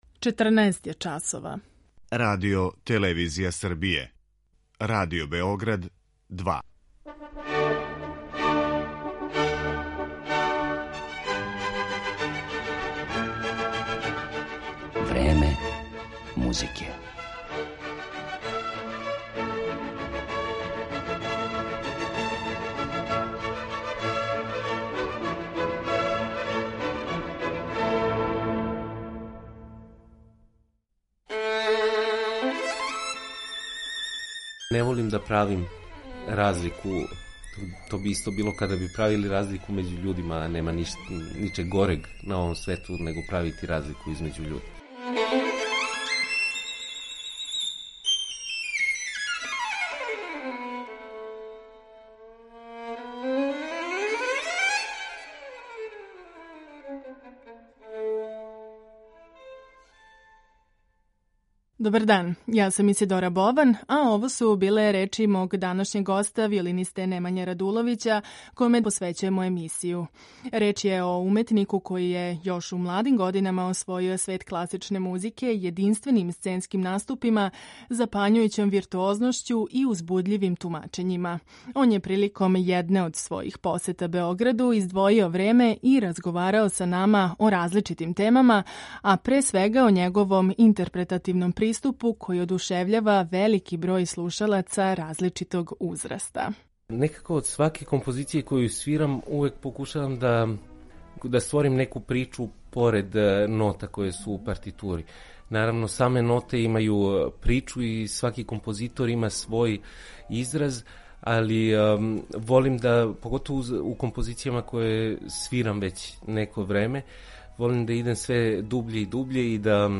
Славни виолиниста бриљантног тона, страствене експресивности и запањујућег виртуозитета Немања Радуловић остварио је изузетан број концерата са цењеним светским оркестрима, реномираним уметницима и својим ансаблима Double Sens и Devil’s Trill, а више о свом уметничком путу откриће у ексклузивном интервјуу.
Он је не само врхунски интерпретатор већ и уметник чији музички укус сеже и изван граница класичне музике, а то ћемо осветлити Радуловићевим тумачењима дела Баха, Паганинија, Римског-Корсакова, Дворжака, Брамса и Вивалдија, као и познатих традиционалних песама.